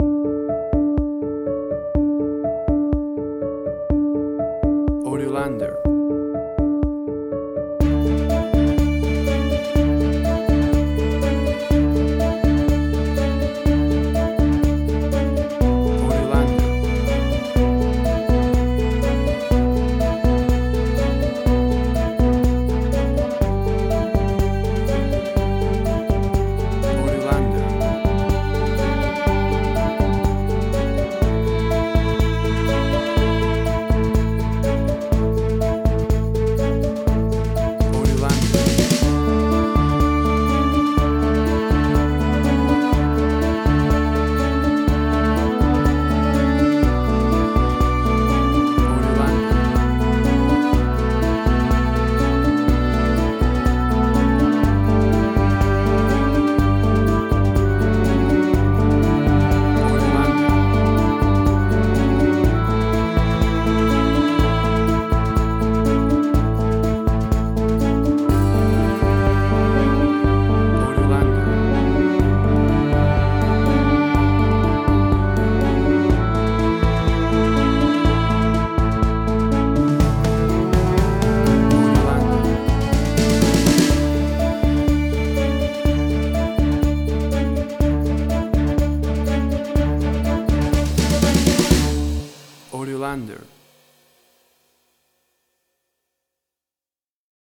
Suspense, Drama, Quirky, Emotional.
Tempo (BPM): 123